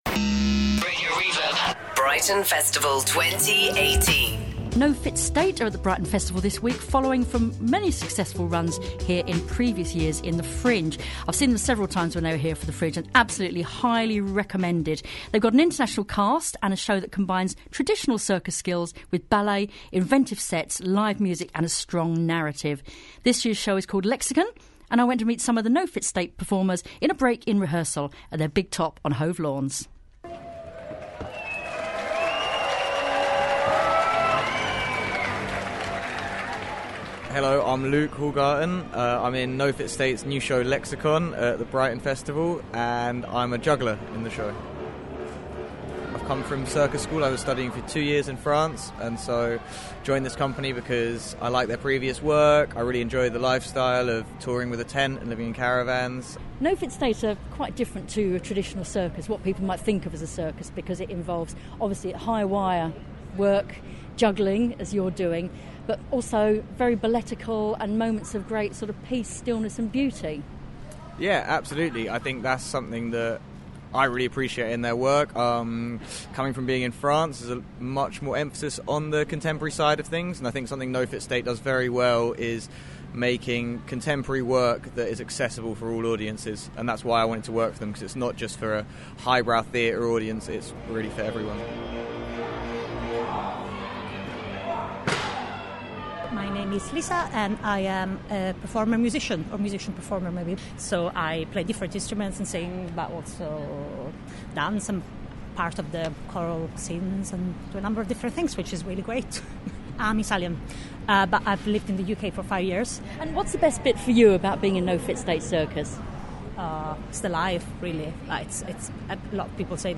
in a break during rehearsals